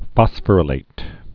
(fŏsfər-ə-lāt)